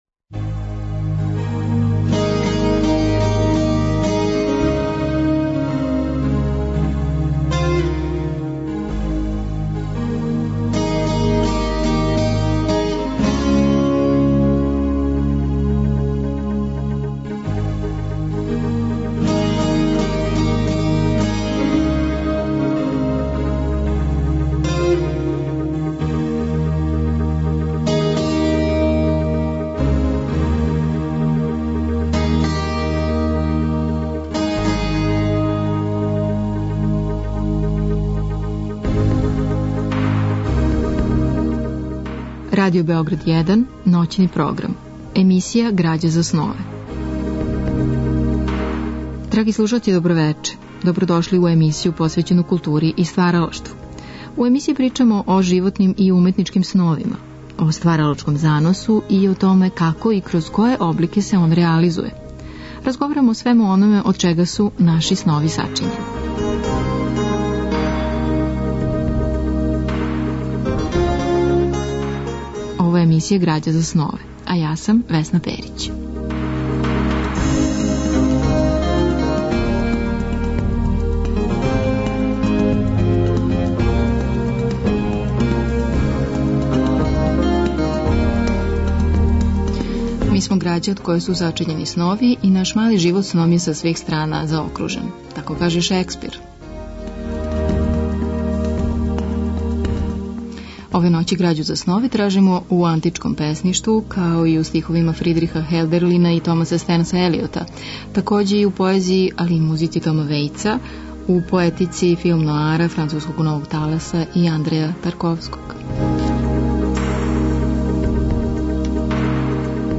Емисија ноћног програма под називом ГРАЂА ЗА СНОВЕ бави се питањима од значаја за уметност и стваралаштво. Гости су људи из различитих професија, они који су и сами ствараоци, и блиска им је сфера духа и естетике. Разговор и добра музика требало би да кроз ову емисију и сами постану грађа за снове.